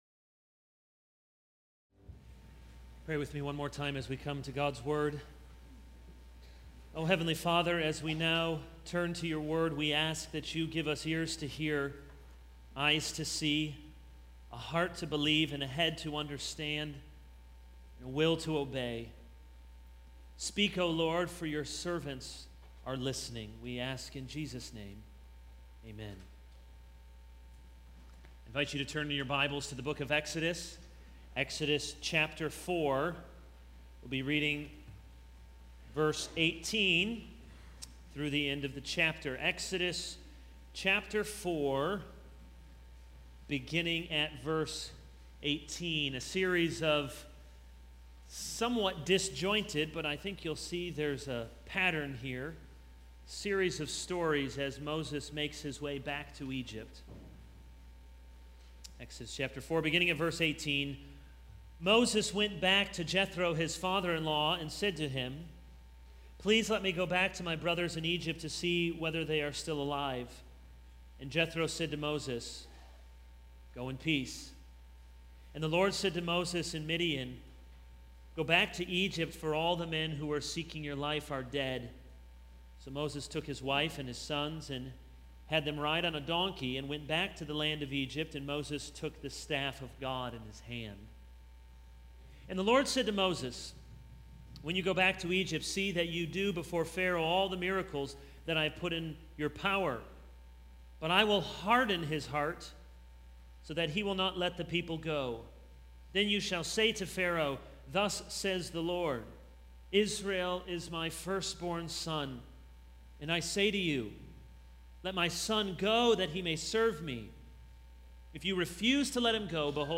This is a sermon on Exodus 4:18-31.